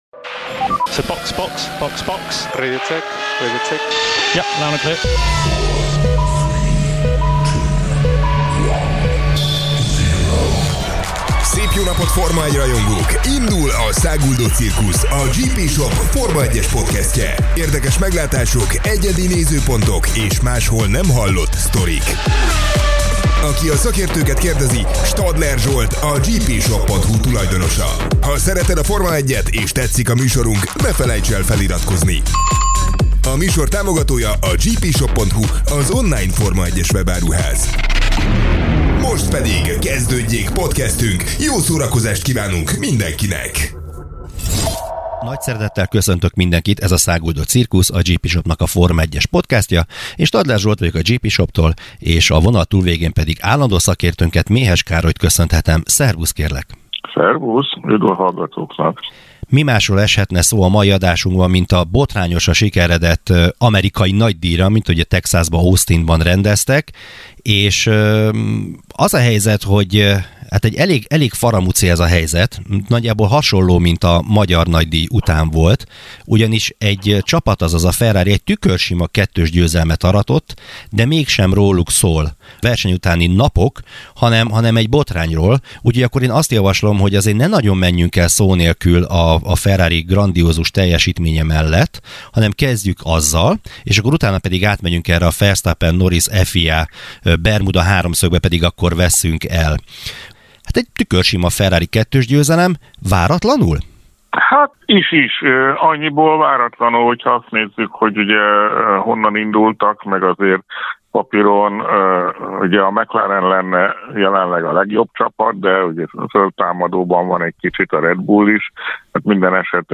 A Ferrari tükörsima kettős győzelméről és a Verstappen és Norris közötti előzést követő vitás FIA döntésről beszélgetem